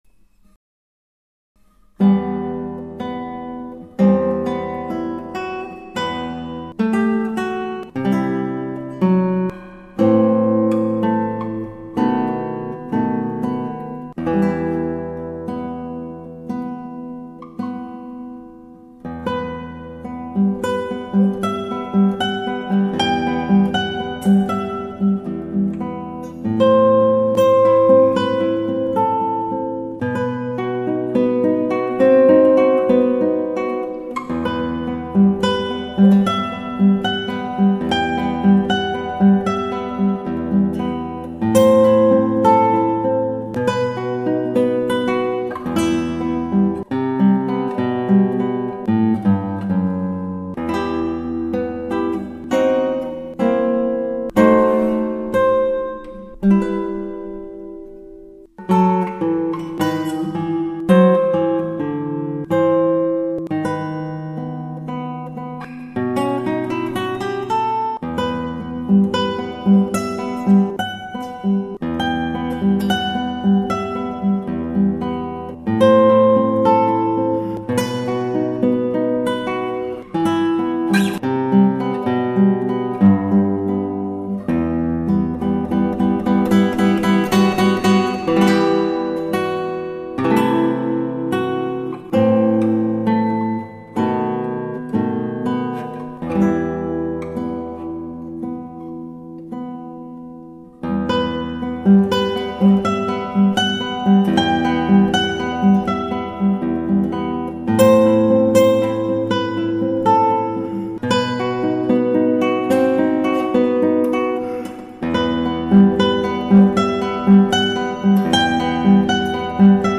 ギターはアルカンヘルで